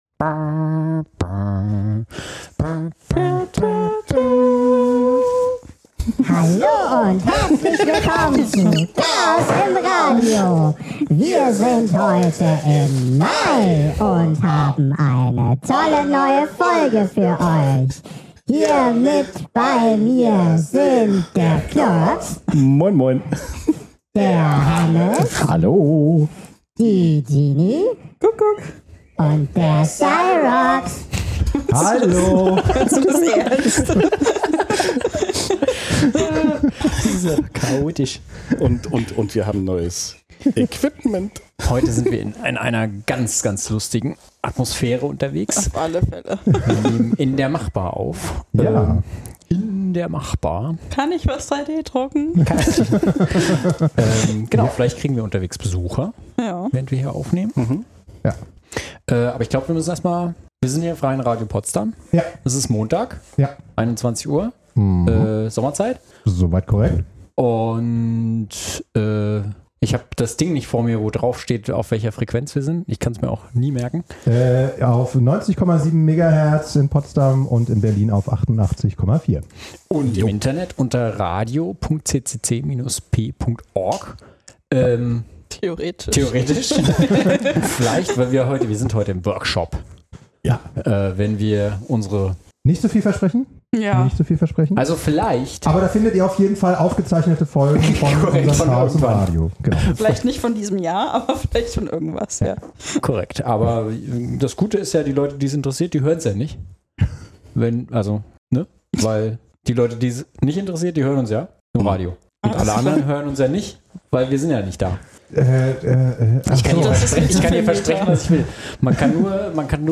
haben sich in der Machbar getroffen um das neue Aufnahmeequipment auszuprobieren